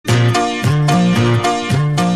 알림음